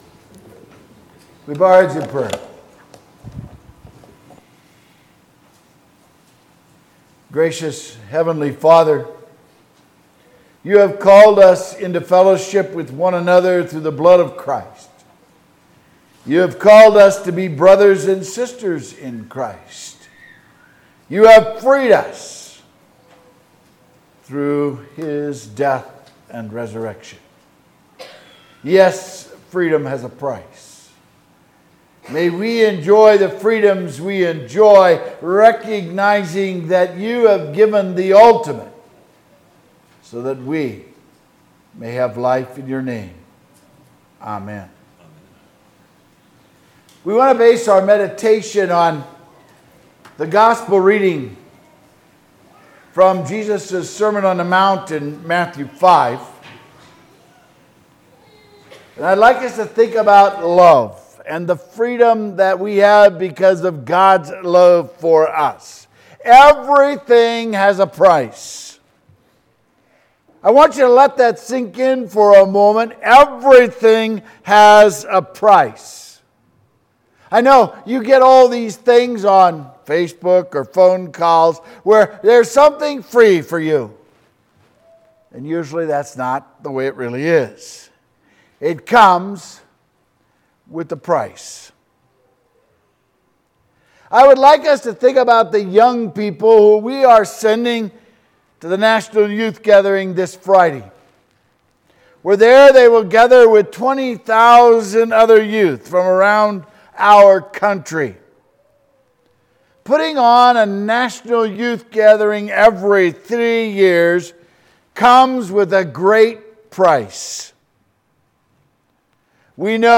Sermon July 3, 2022